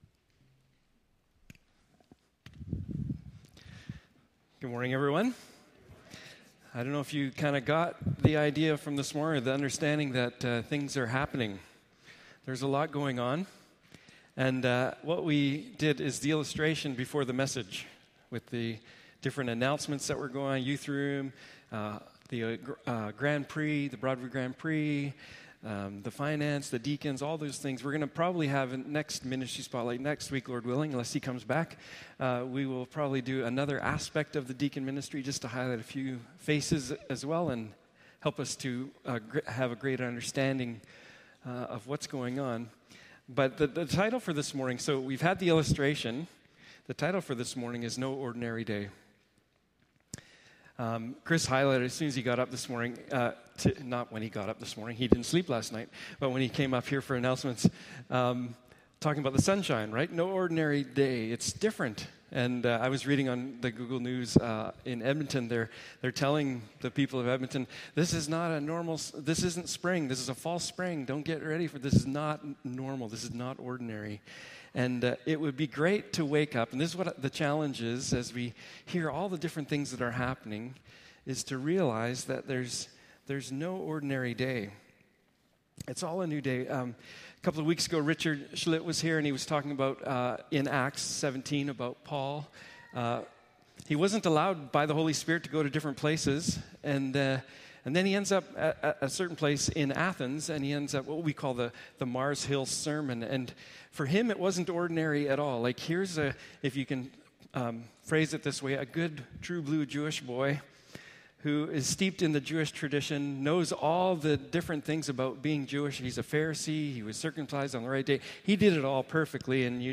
Acts 3 Service Type: Morning Service « God’s Full Salvation Acts of Jesus